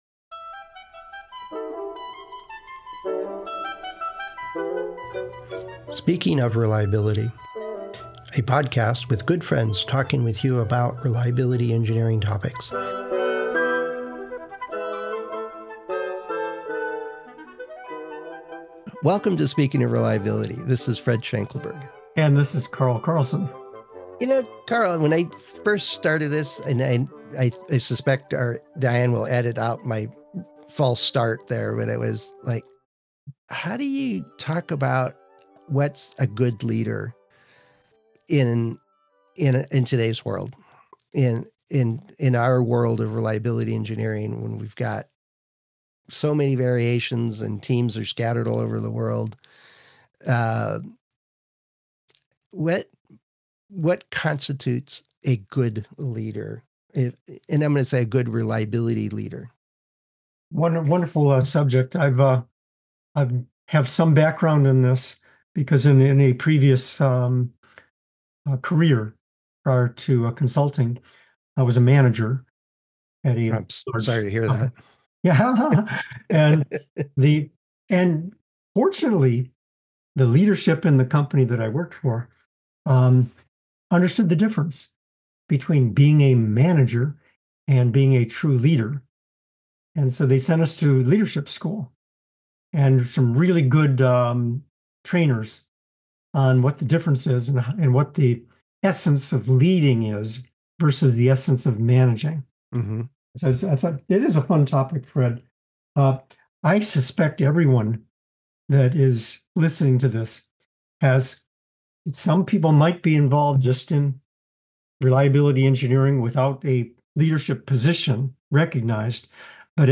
Friends Discussing Reliability Engineering Topics